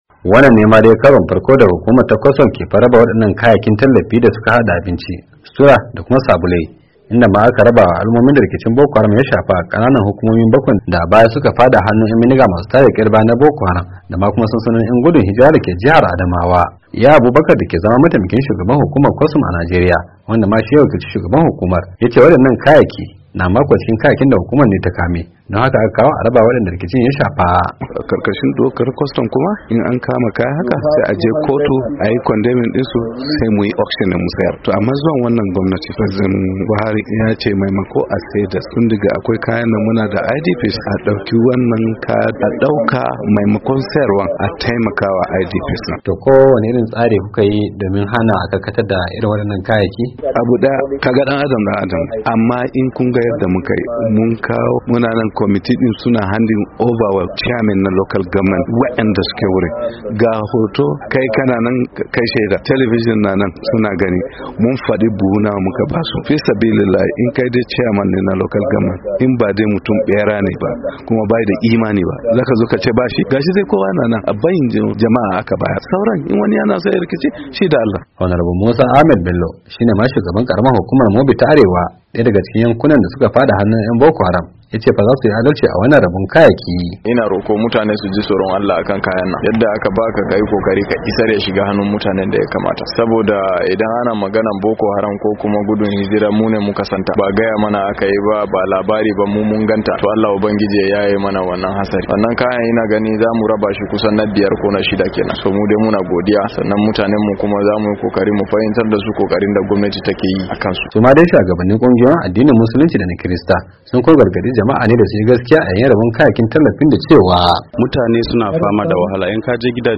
Iya Abubakar shi ne mataimakin shugaban hukumar ta kwastam ,da ya wakilici shugaban hukumar,yace wadannan kayaki na ko cikin kayakin da hukumar ta kame,don haka ta kawo a rabawa wadanda rikicin ya shafa.
Shiko Hon. Musa Ahmad Bello shugaban karamar hukumar Mubi ta Arewa,daya daga cikin yankunan da suka fada hannun yan Boko Haram,yace zasu yi adalci a rabon kayakin.